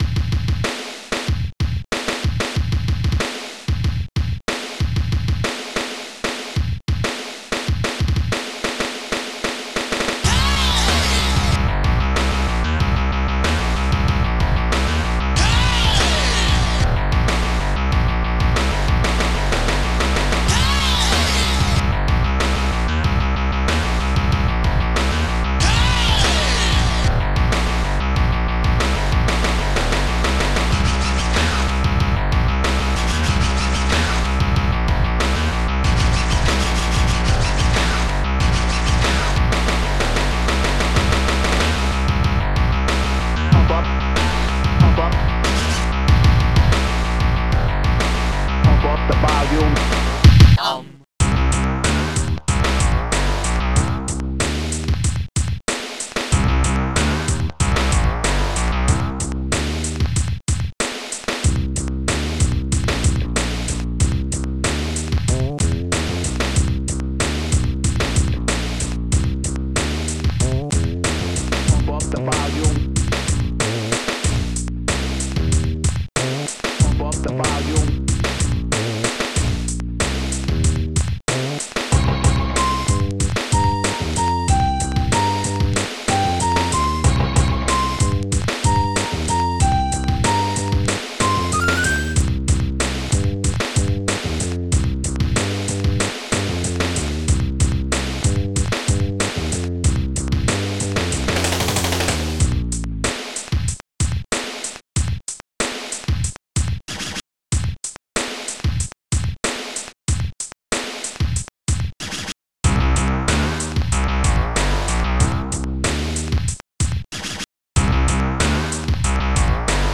st-04:SP12BassDrum
st-15:Snare2
st-02:HammerBass
st-50:scratch
st-15:HighHat2